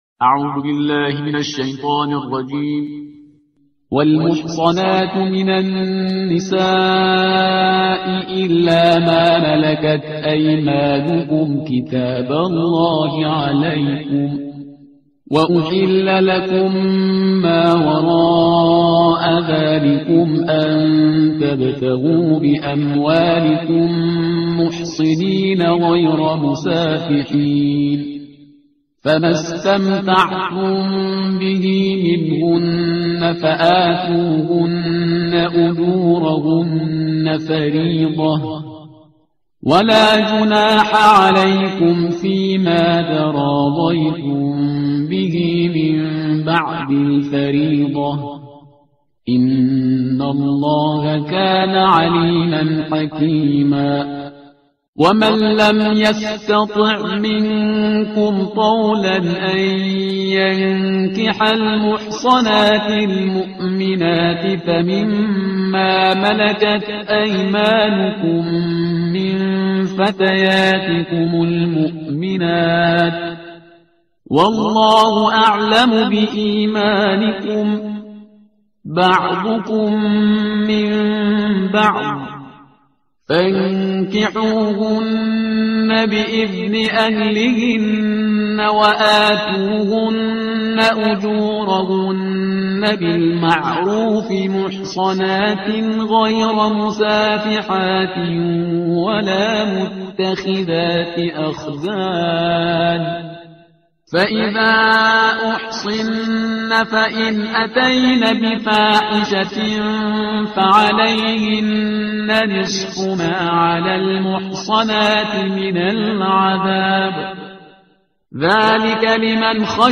ترتیل صفحه 82 قرآن – جزء پنجم